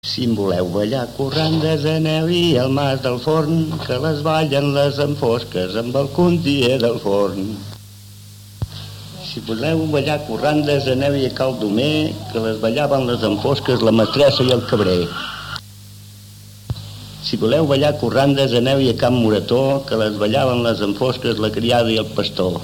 35_Corrandes_Oix-OK.mp3